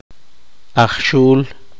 [ʔaχ.’ʃu:l] sustantivo flautista